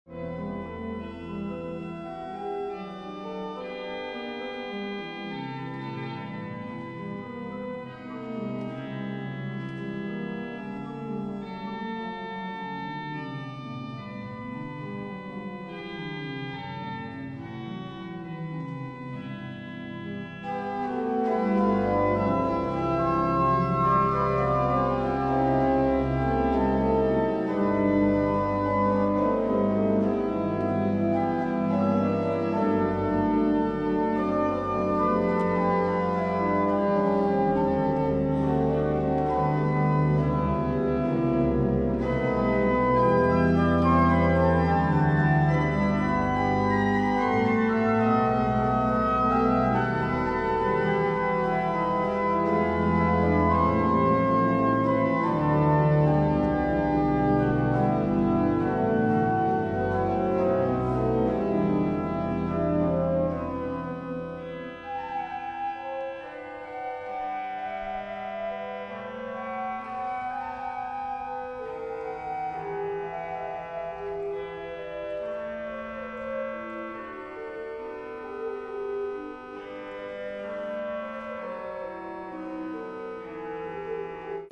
Concert sur l'orgue Aubertin de l'église Saint-Louis à Vichy
Les extraits montrent quelques échantillons des sonorités particulières de l'orgue.